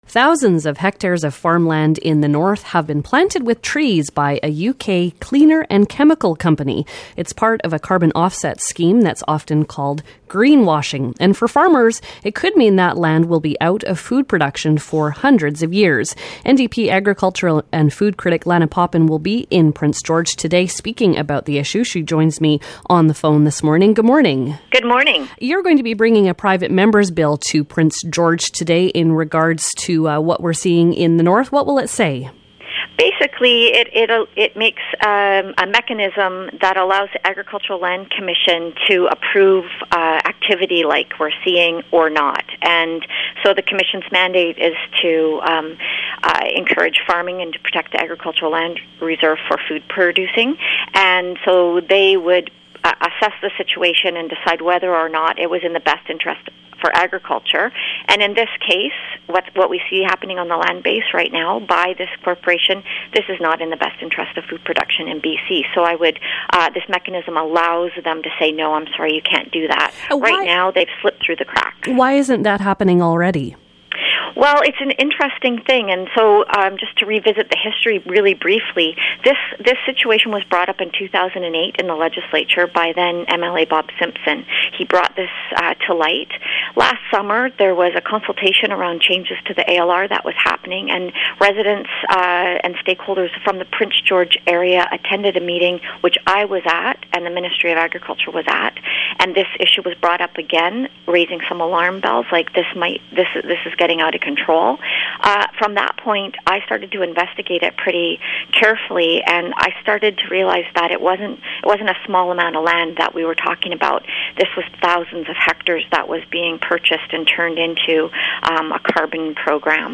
BCNDP agriculture and food critic Lena Popham is critical of a carbon offset scheme that is converting Northern B.C. farms in to new growth forests. We speak with Popham and agriculture minister Norm Letnick.